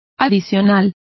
Complete with pronunciation of the translation of plus.